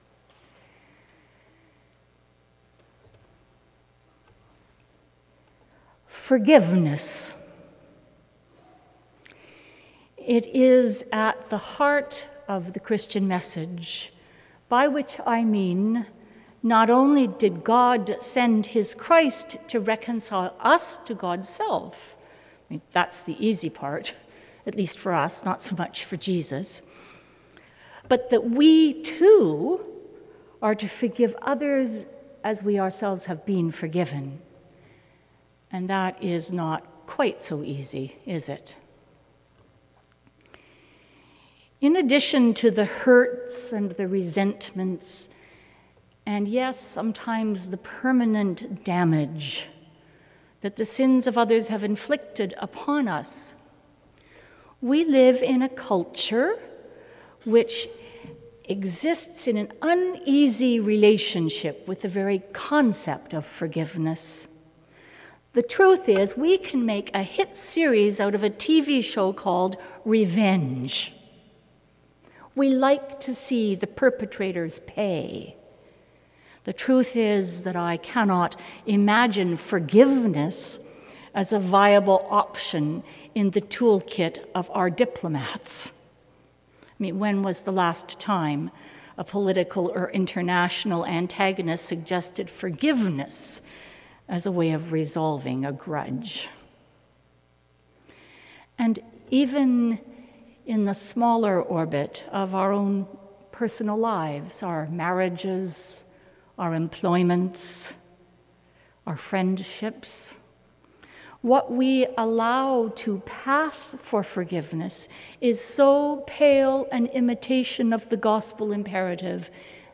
Sermon: 10.30 a.m. service